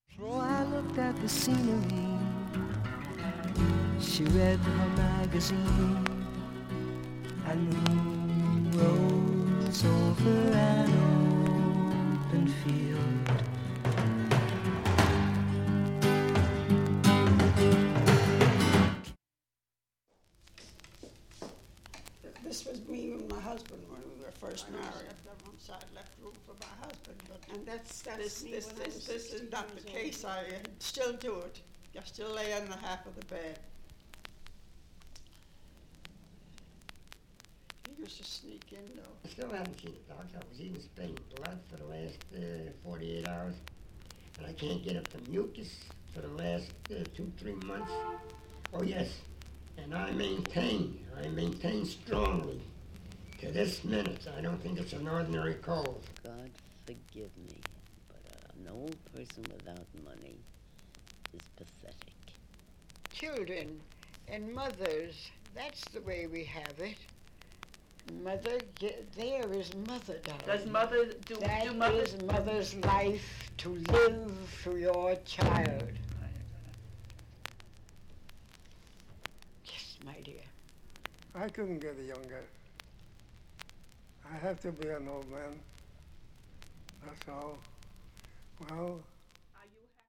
B-1B-2曲間にかすかにチリ出ますが
５回までのかすかなプツが４箇所
３回までのかすかなプツ７箇所
単発のかすかなプツが７箇所
◆UK盤オリジナル Stereo